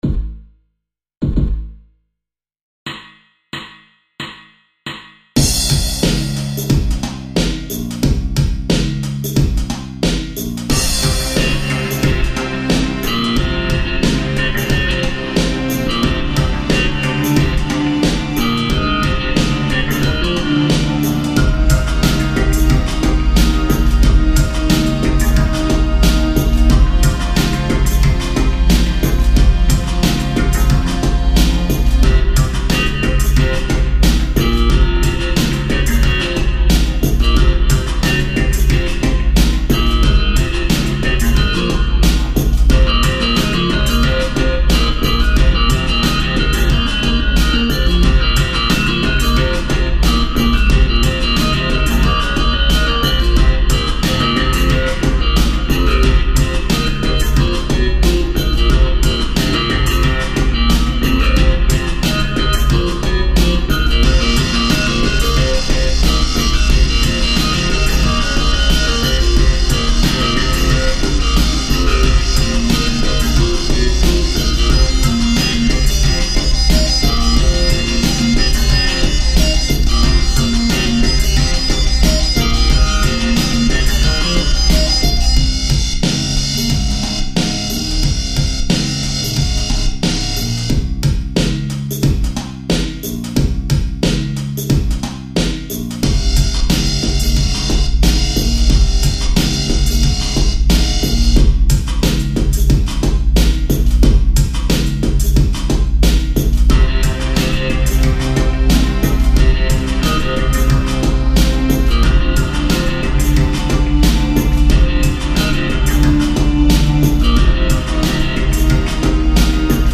more beats which are fresh, performed by the dinosaur comics dinosaurs